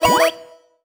collect_coin_02.wav